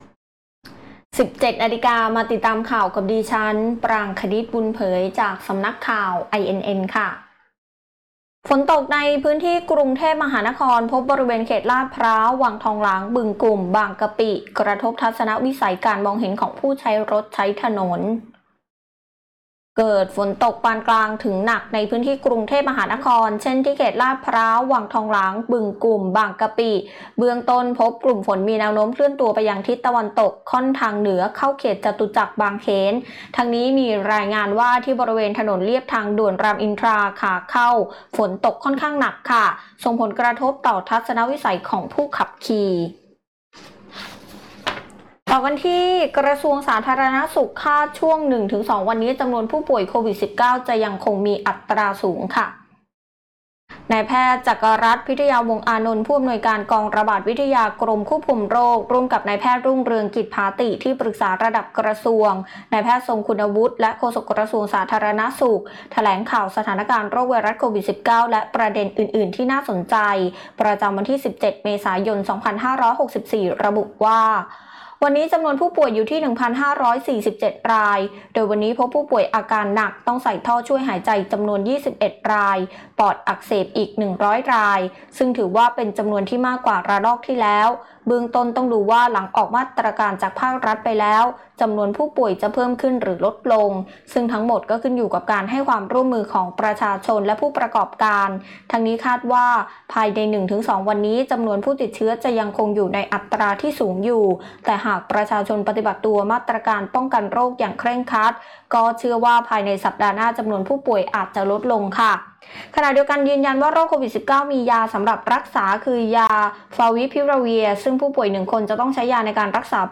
ข่าวต้นชั่วโมง 17.00 น.